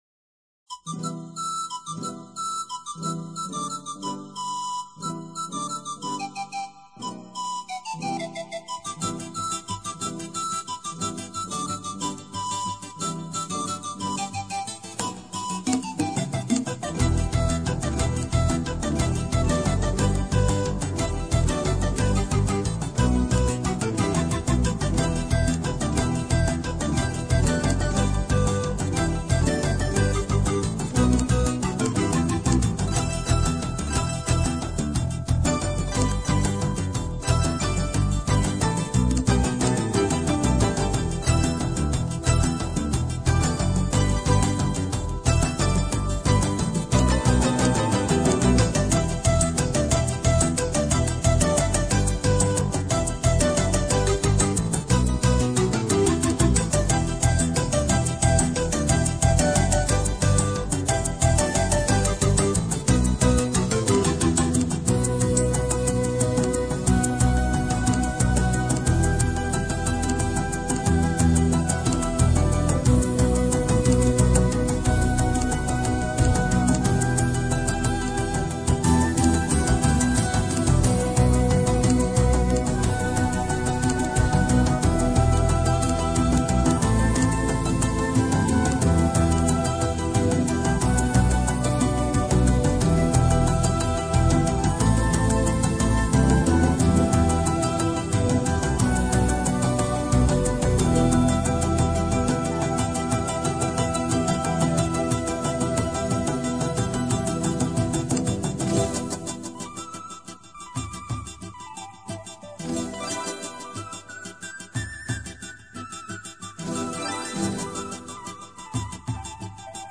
German keyboard-based group
The joyful sound of synthesized panpipes and flutes
catchy melodies and playful spirit of celebration
pop-oriented new age